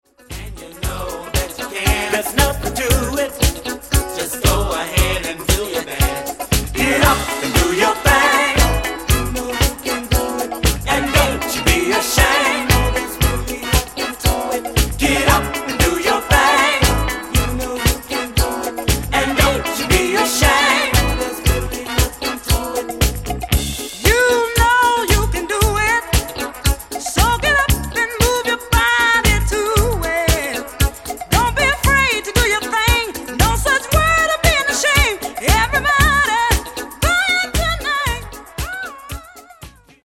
12''Mix Extended
Disco Funk e Dance Clssics degli anni 70 e 80.